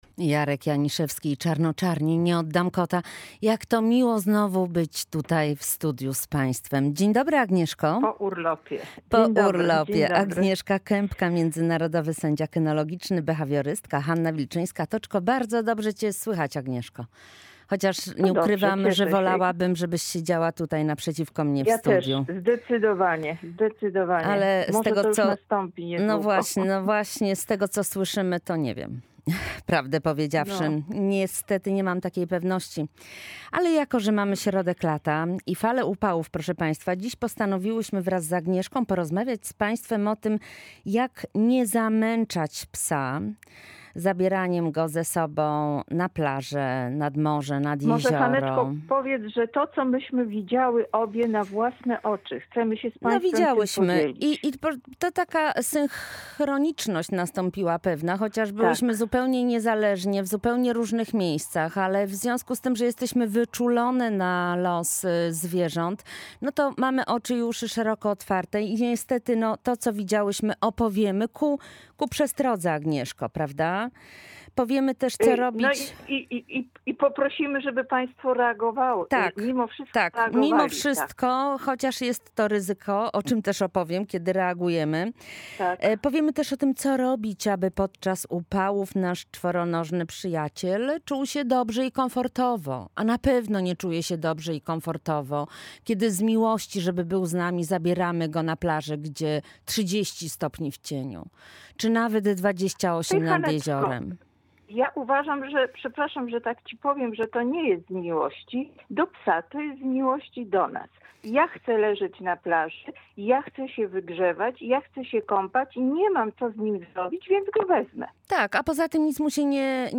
Prowadzące program apelowały, żeby zwracać uwagę i zwiększać świadomość właścicieli zwłaszcza dużych, kudłatych psów. Dni, w których temperatura w cieniu wynosi 30 stopni Celsjusza, mogą być niebezpieczne dla naszych zwierzaków.